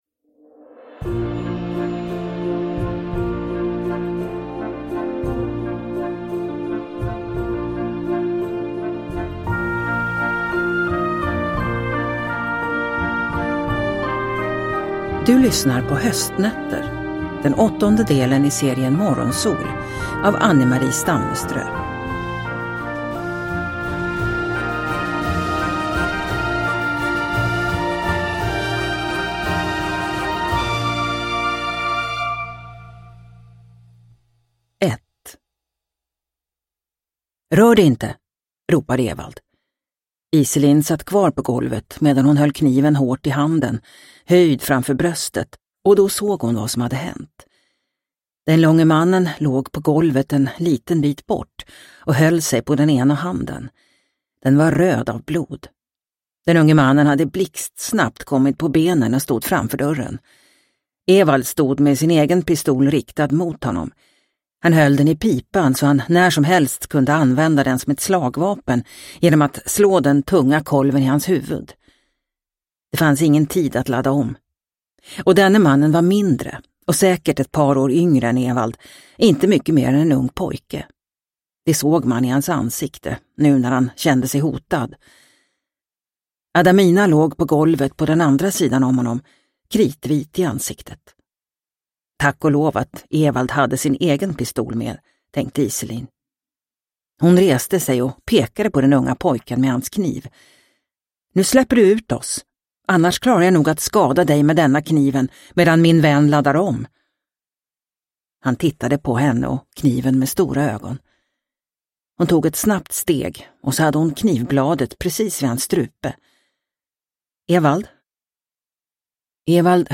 Höstnätter – Ljudbok – Laddas ner